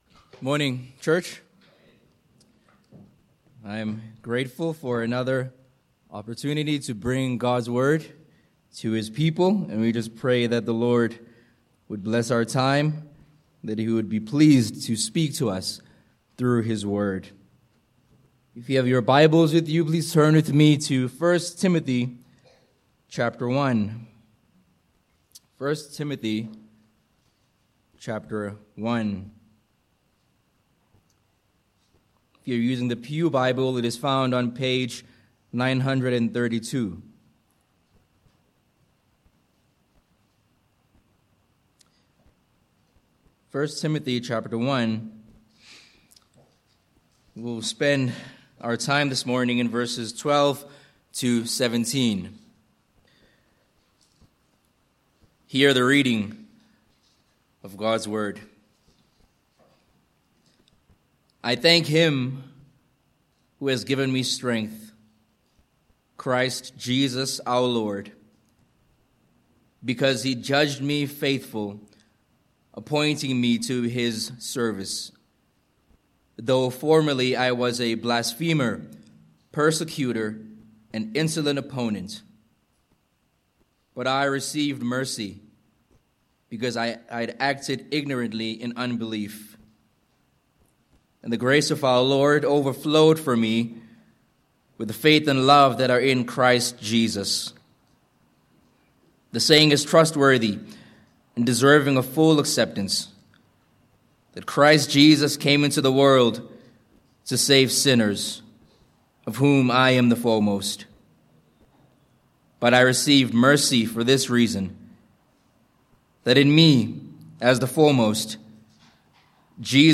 Sermon Points: 1. Saved to Serve v12